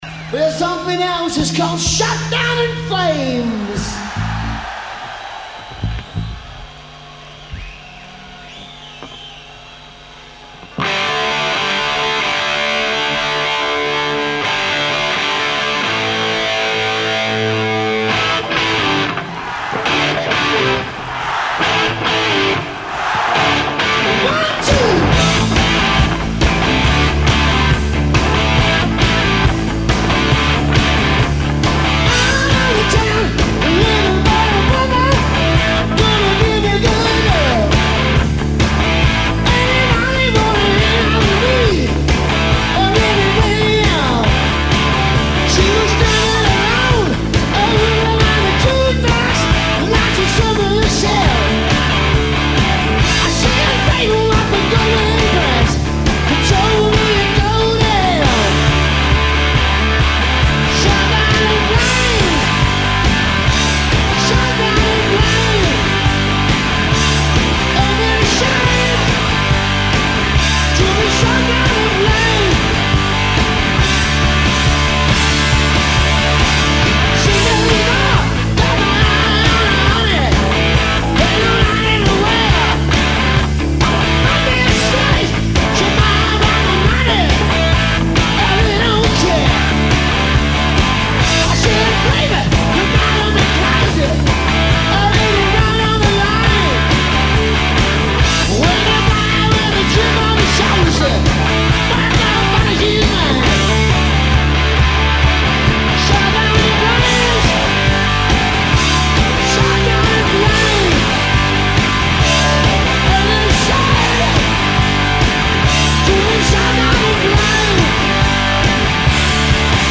white-hot live performance
in Paris 1979